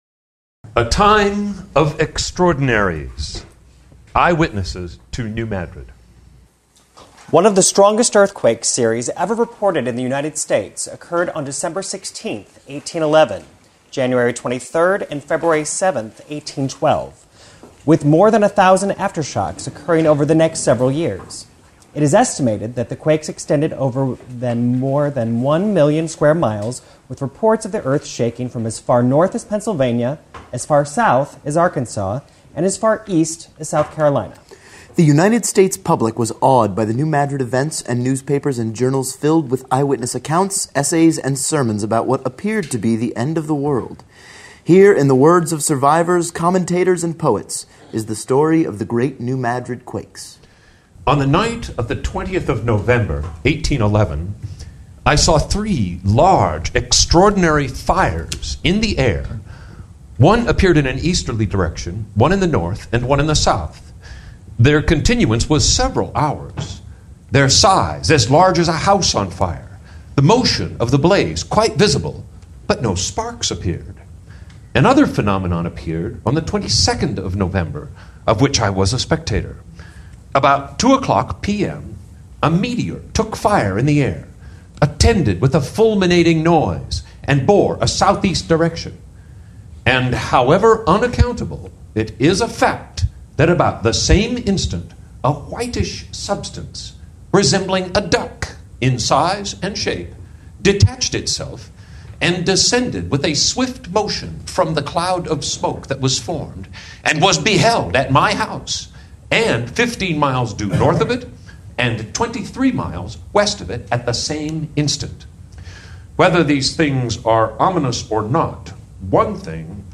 Conference speakers explored what Missouri was like in 1811, what occurred during the earthquakes, various theories about whether earthquakes of that magnitude will happen again, and, if they do, whether Missouri is ready. A reader's theatre presented eyewitness accounts of the 1811 - 1812 quakes and their aftermath.